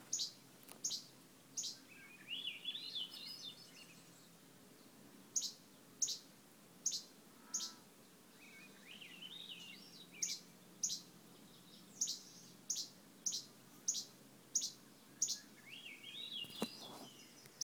Field Notes – Grant Narrows, June 30 & July 13, 2013
record a calling Least Flycatcher about 1.5 km from the trailhead.